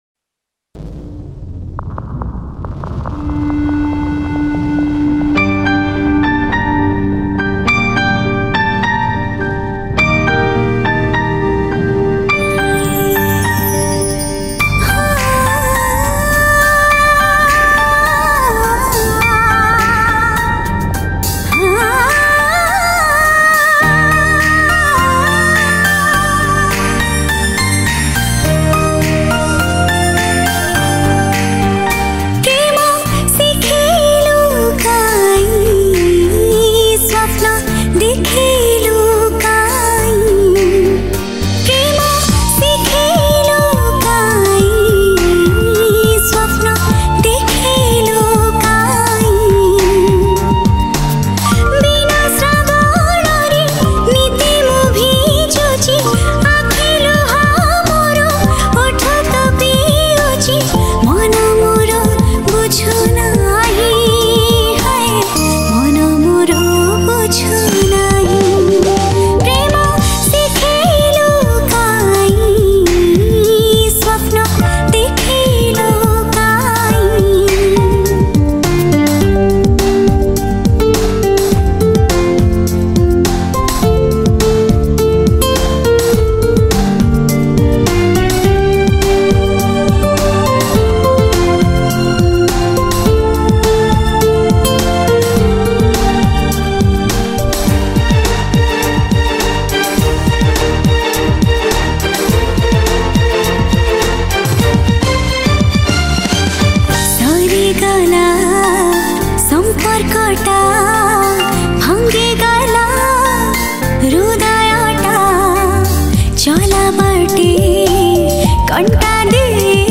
New Odia Sad Song